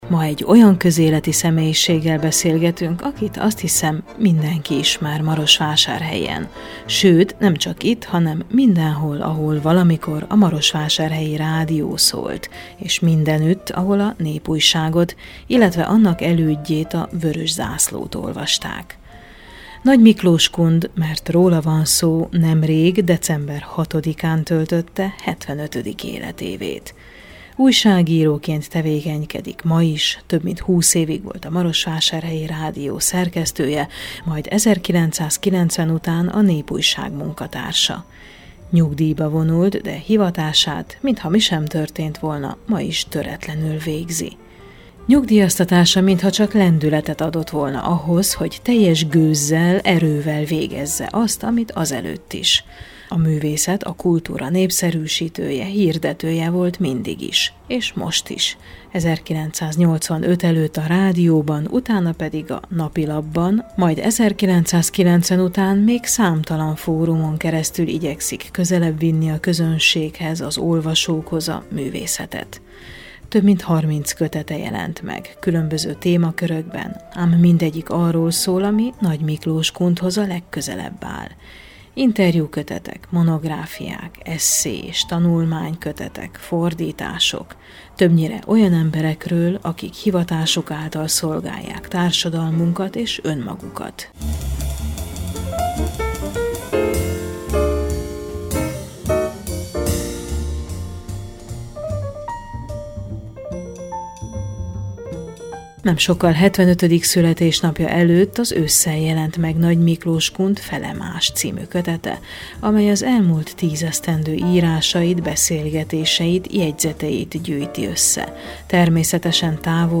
Születésnapi beszélgetés